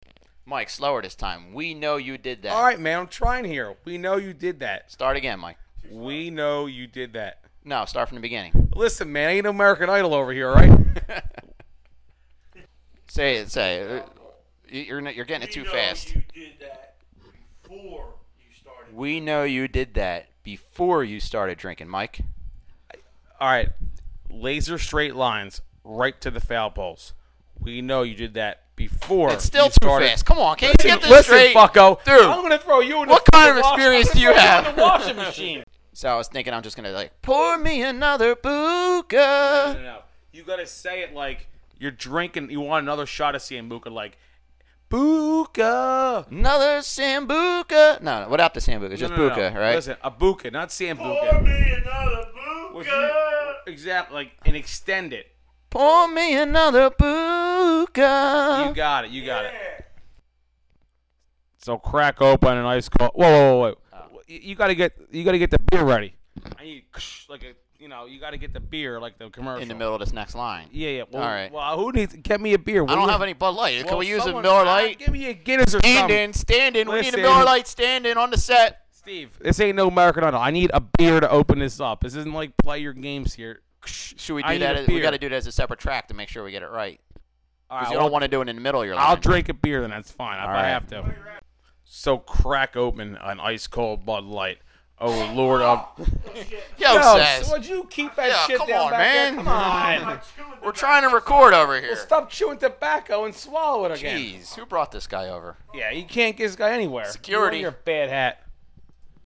Scud Light Beer Commerical Outtakes